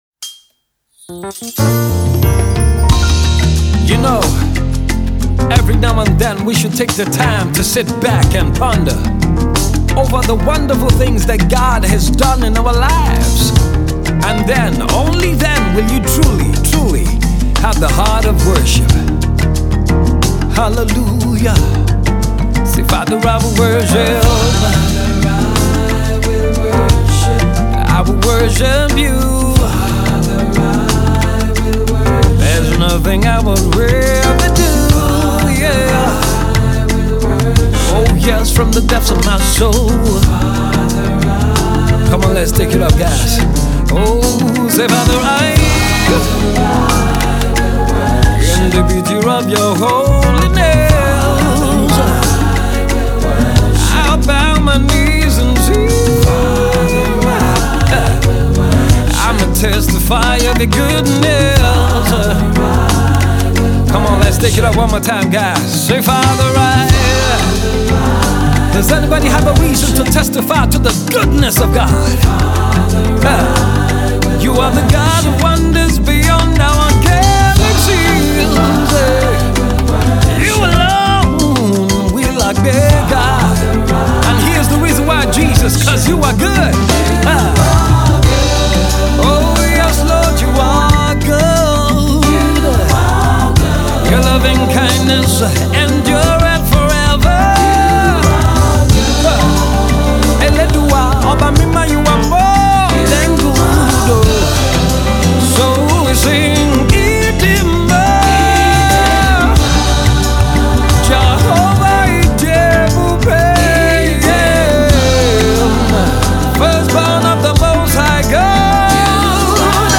the song is a soul stirring masterpiece of worship